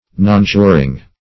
Search Result for " nonjuring" : The Collaborative International Dictionary of English v.0.48: Nonjuring \Non*ju"ring\, a. [F. jurer to swear, or L. jurare, jurari, to swear, fr. L. jus, juris, right, law, justice.